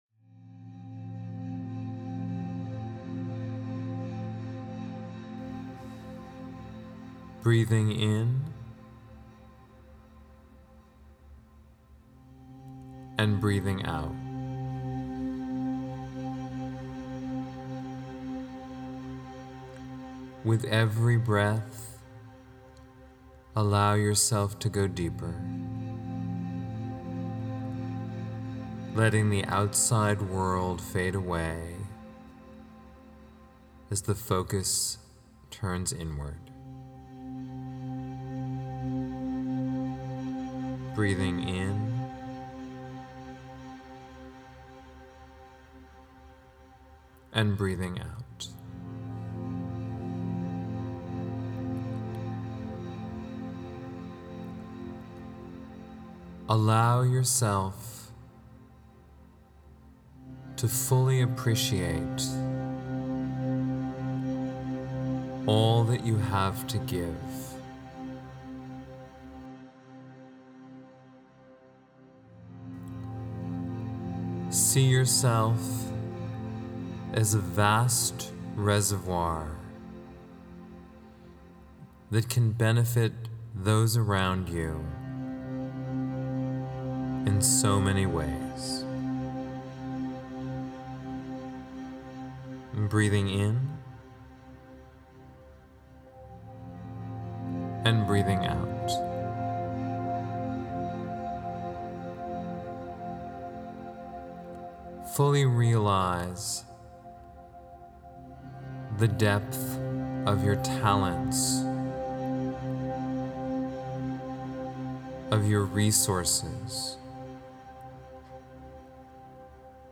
Giving-Meditation-1-1.mp3